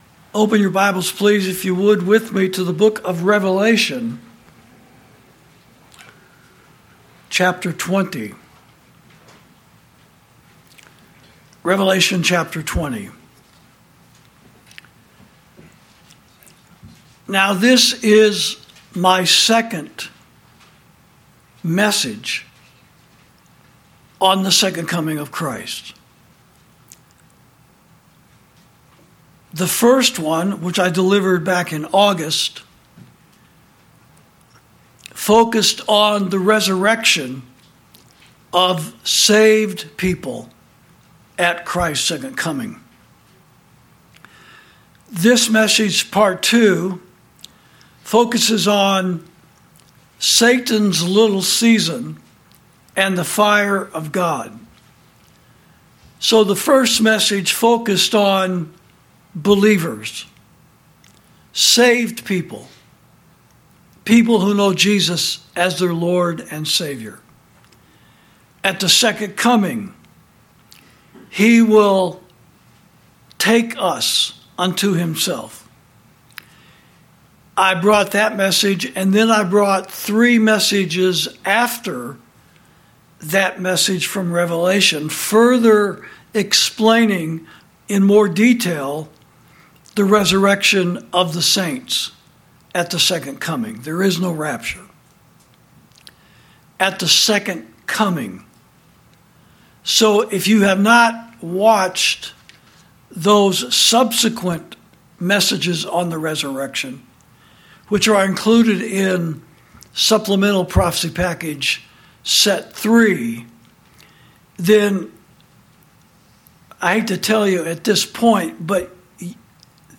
Sermons > The Second Coming Of Christ—Part Two—Satan's Little Season And The Fire Of God (Prophecy Message Number Twenty-Six)